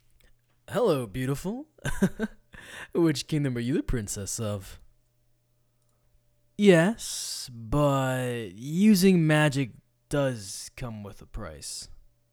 Voice Actor
Voice: Flirtatious, caring, medium pitched range.
HumphreyAudition.wav